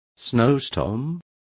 Complete with pronunciation of the translation of snowstorms.